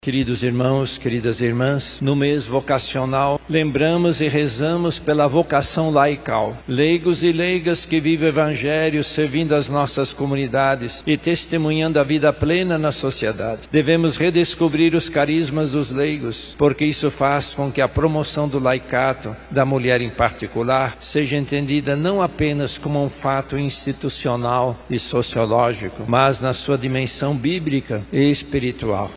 No 21º Domingo do Tempo Comum, durante a homilia celebrada na Catedral Metropolitana de Manaus, o Arcebispo, cardeal Leonardo Steiner, reforçou o valor da participação ativa dos leigos na missão evangelizadora da Igreja.